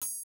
{Perc} lose 1.wav